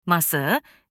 معنی و تلفظ کلمه “میز” به ترکی استانبولی